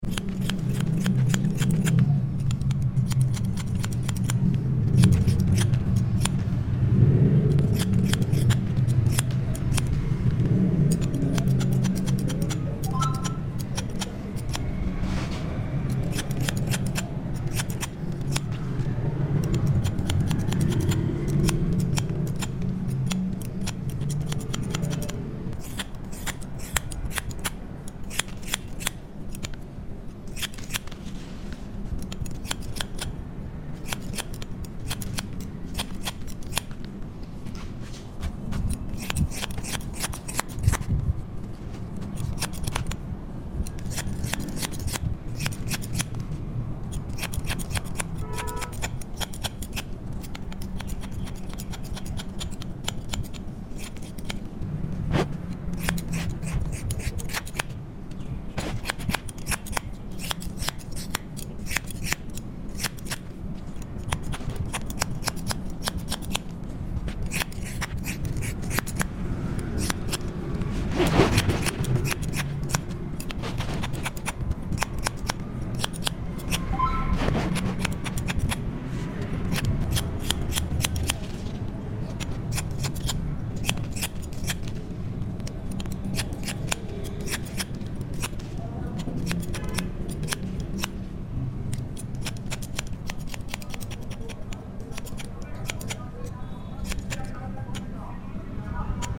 Full ASMR Relaxing Haircut | Sound Effects Free Download